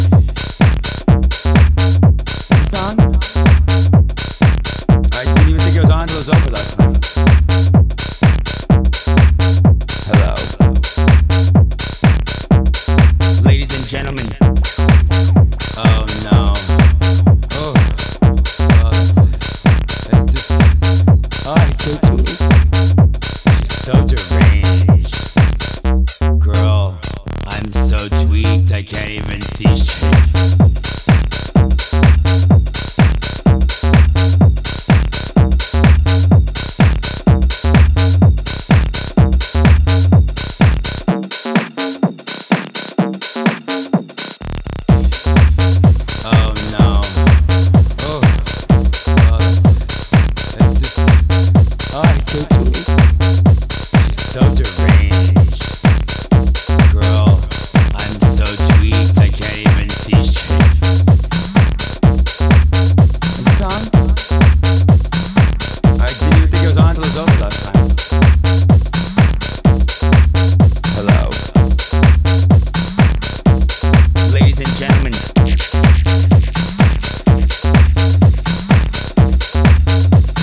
house club bangers
Expect raw cuts, acid nostalgia and Chicago vibes.